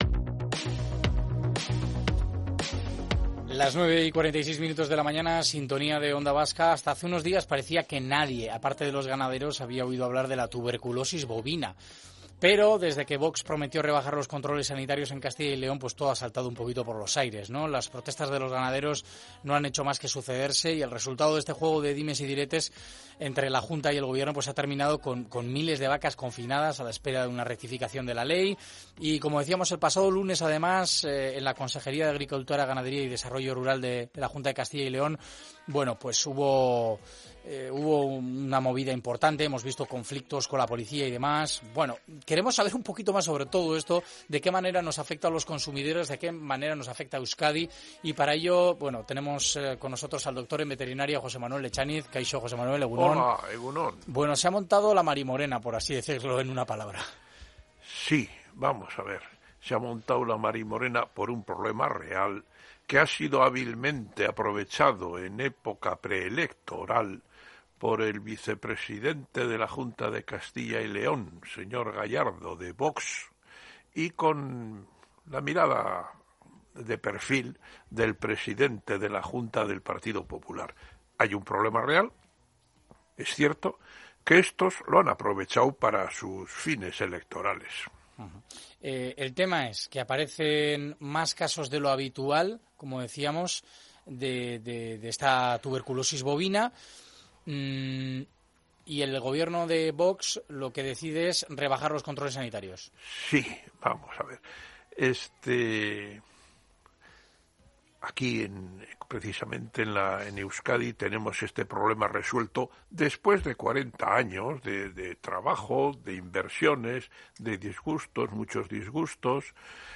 doctor en veterinaria